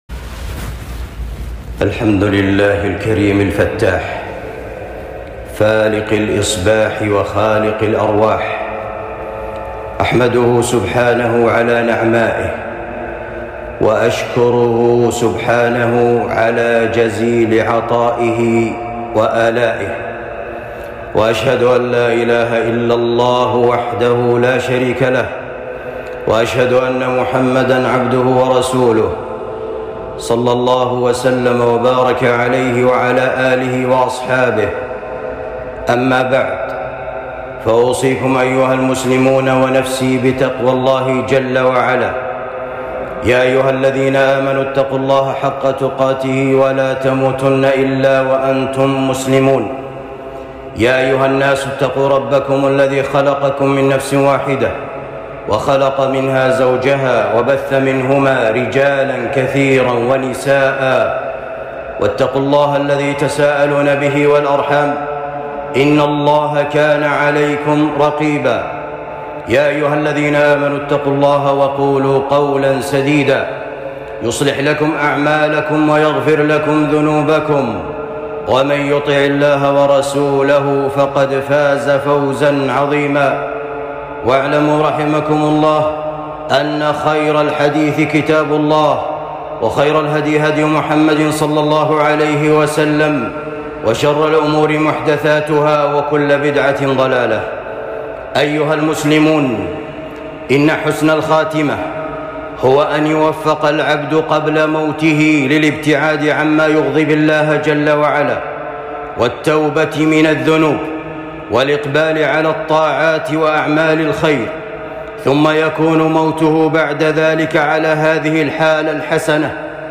خطبة بعنوان علامات وأسباب حسن الخاتمة